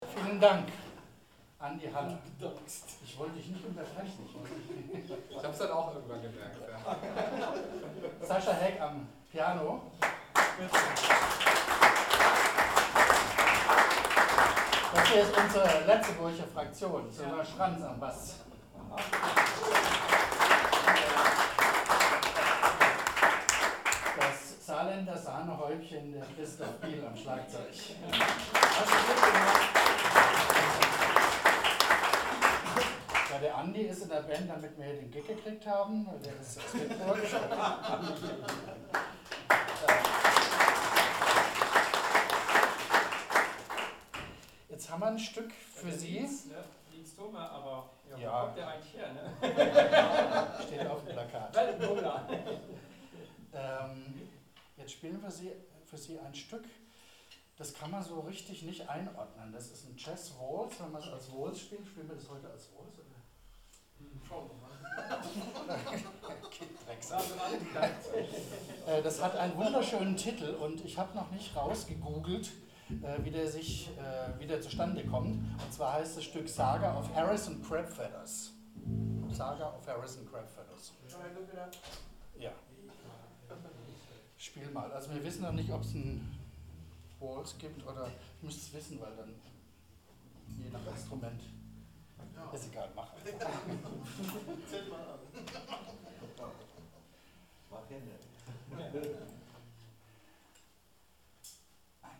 10 10 Ansage [01:42]